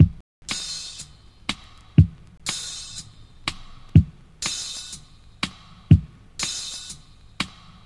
• 122 Bpm Drum Loop D# Key.wav
Free breakbeat sample - kick tuned to the D# note. Loudest frequency: 1761Hz
122-bpm-drum-loop-d-sharp-key-KqC.wav